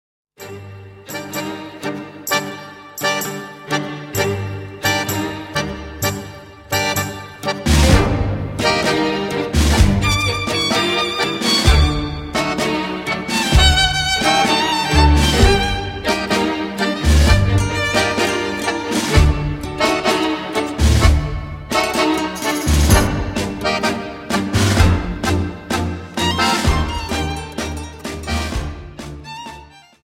Dance: Tango